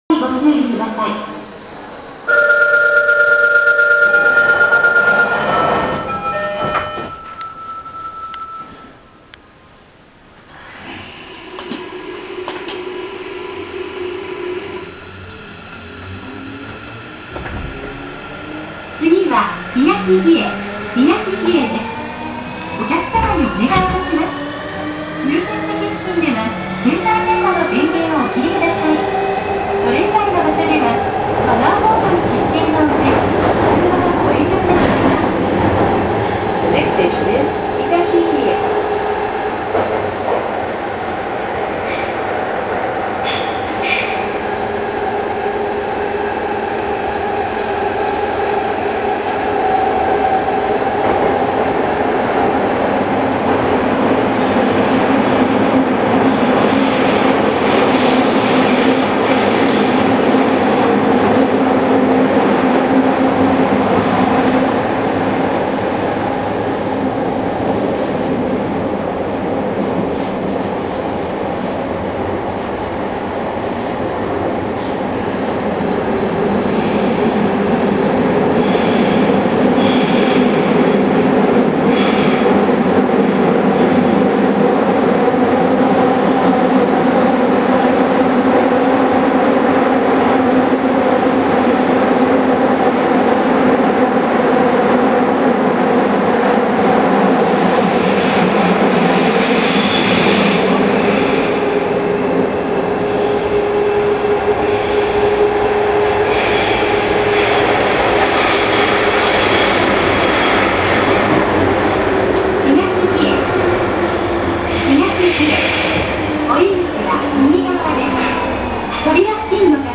JR各車の量産型車両としては初めてPMSMを採用しており、走行音にやや特徴があります。
・305系走行音
【福岡市営空港線】博多→東比恵（2分16秒：740KB）
東芝PMSMを採用しているので、起動音がかなり変わった音となっています。
地下鉄線内の自動放送にももちろん対応しています。
…が、このドアチャイム。